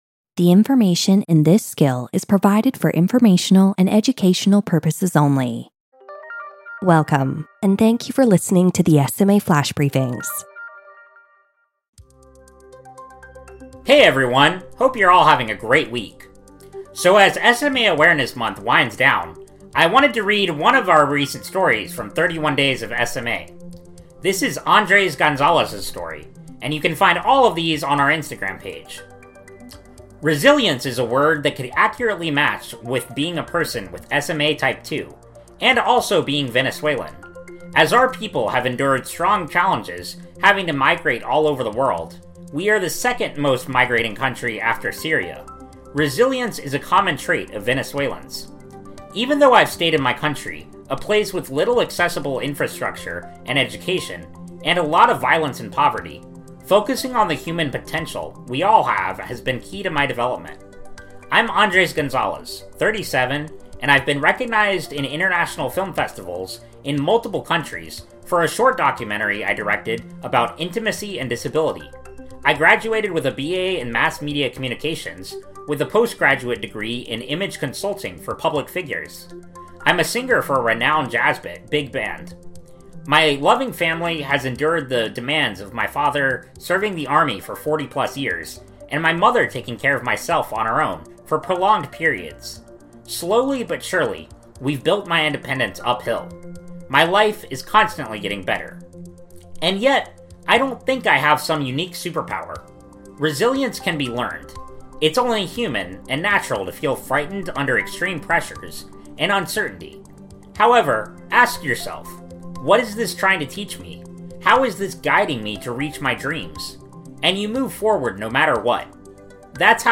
reads from a recent 31 Days of SMA story.